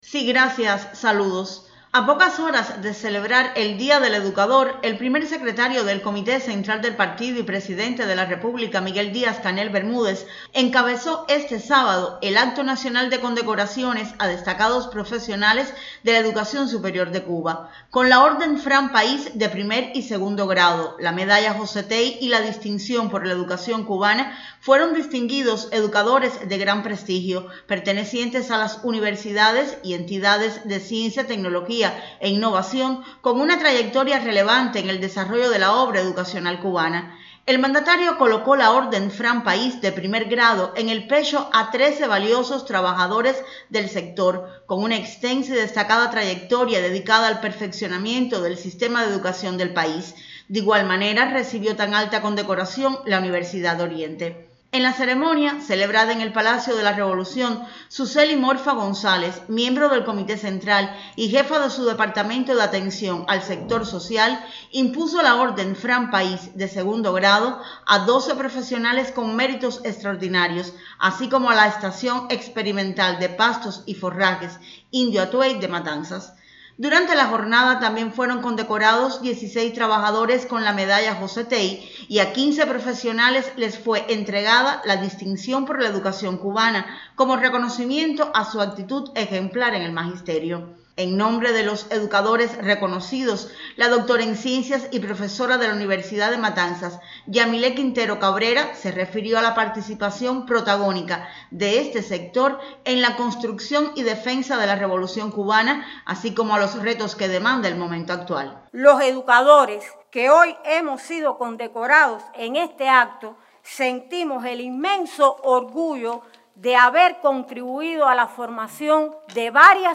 En la tarde de este sábado fueron condecorados en el Palacio de la Revolución destacados educadores del país. La ceremonia estuvo encabezada por el Primer Secretario del Comité Central del Partido Comunista y Presidente de la República, Miguel Díaz-Canel Bermúdez